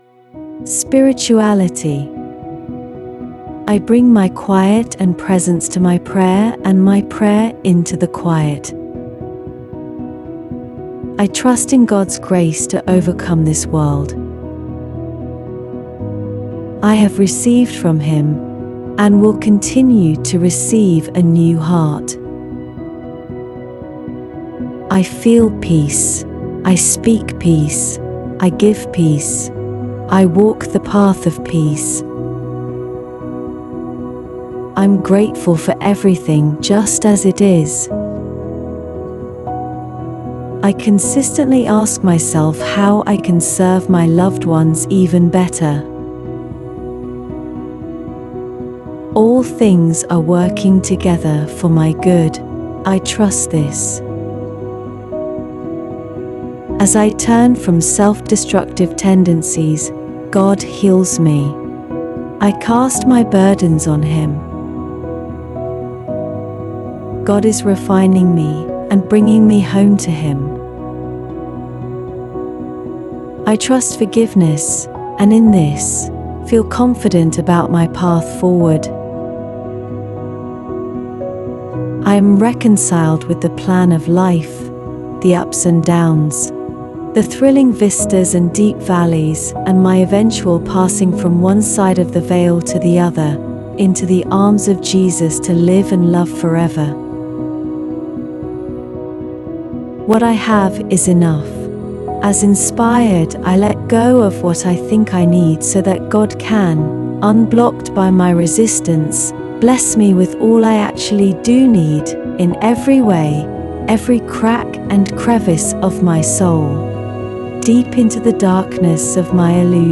with music